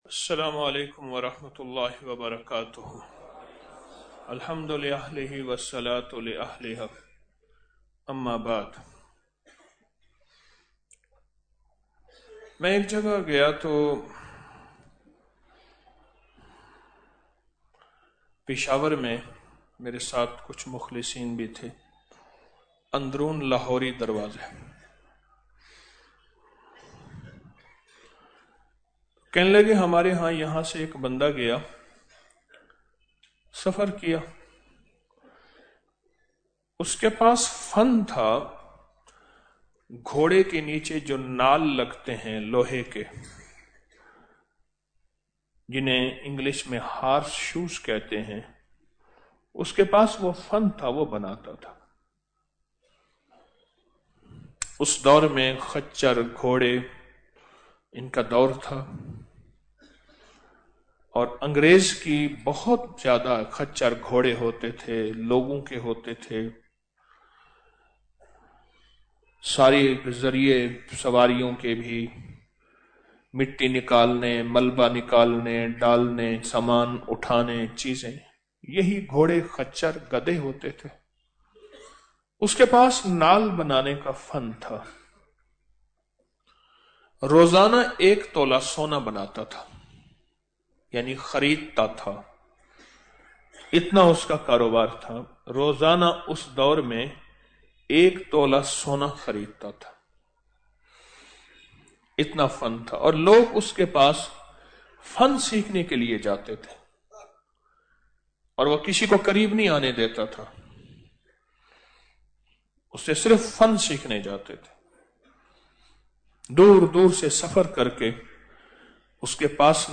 Audio Speech - 10 Ramadan After Salat Ul Taraveeh - 10 March 2025